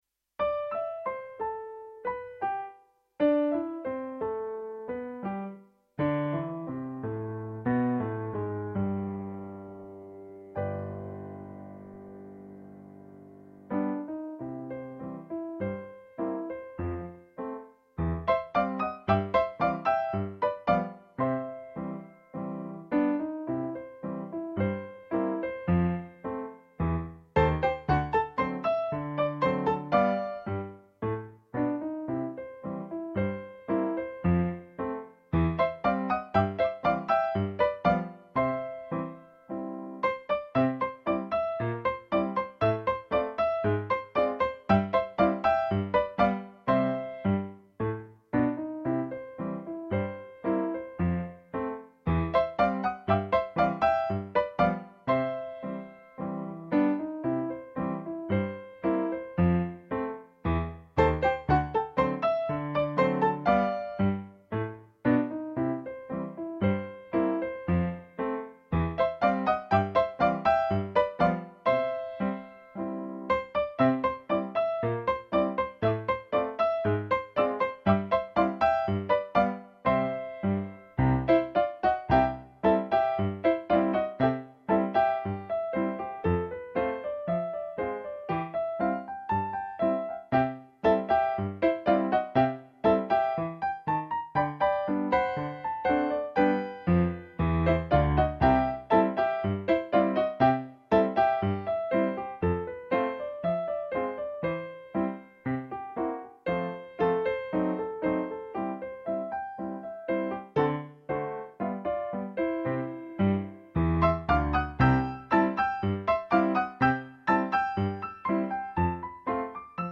Ragtime
Si tratta ovviamente di esecuzioni fatte da un dilettante autodidatta quale sono io, ma animato da molta, moltissima passione per questo genere di musica.